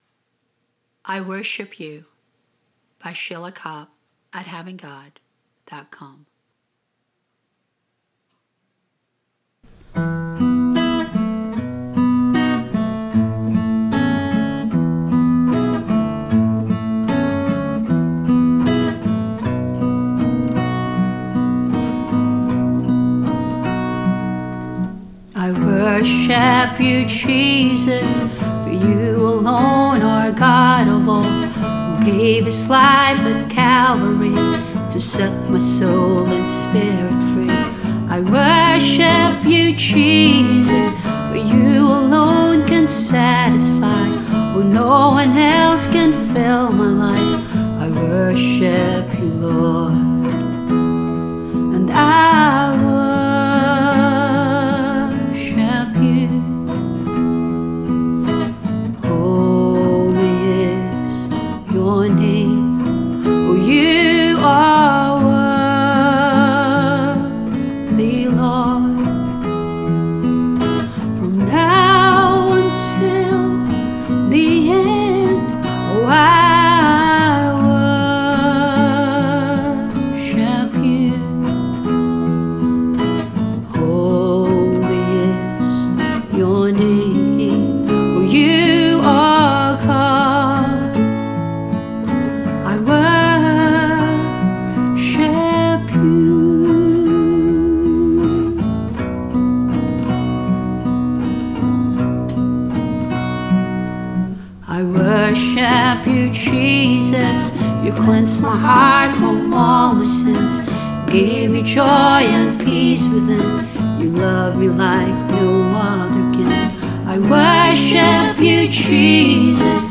Vocals, guitar, bass and bongos
Strings/keyboard